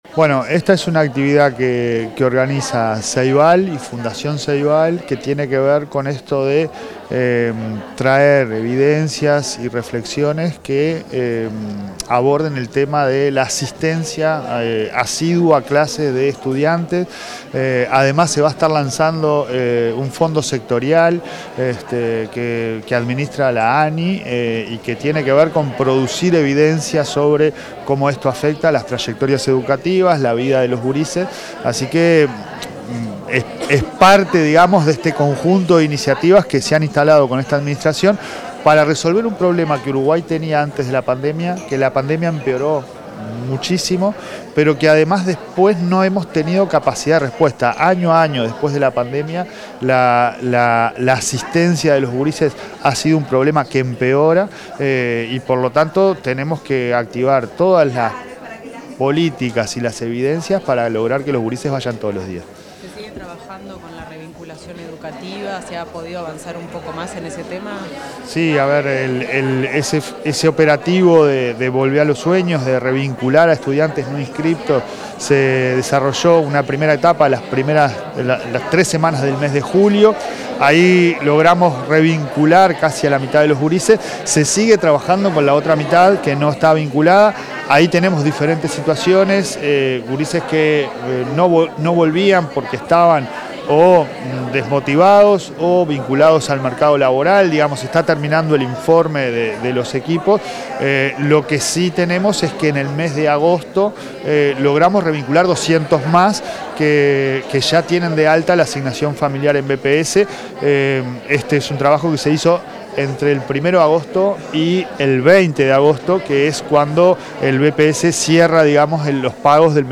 Declaraciones del presidente de ANEP, Pablo Caggiani
Declaraciones del presidente de ANEP, Pablo Caggiani 27/08/2025 Compartir Facebook X Copiar enlace WhatsApp LinkedIn El presidente de la Administración Nacional de Educación Pública (ANEP), Pablo Caggiani, dialogó con los medios de prensa, tras participar en el lanzamiento del Fondo Sectorial de Educación: Inclusión Digital.